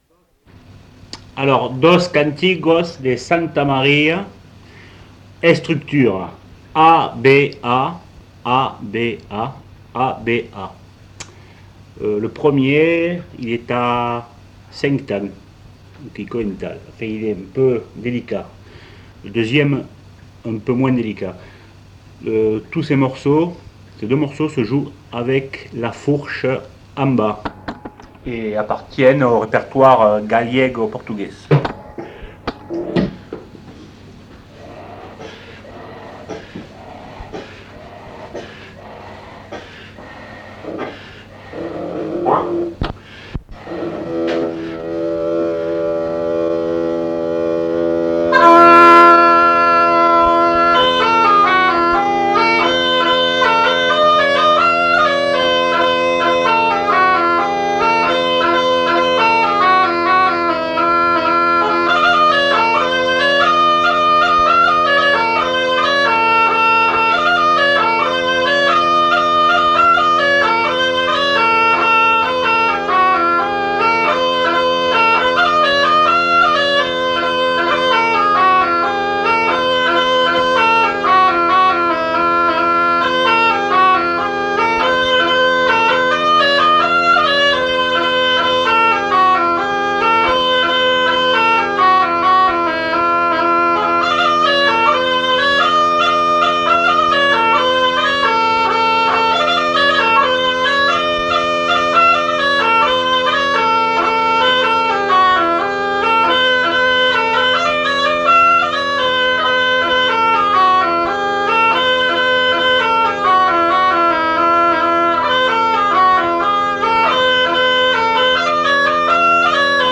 Aire culturelle : Cabardès
Genre : morceau instrumental
Instrument de musique : craba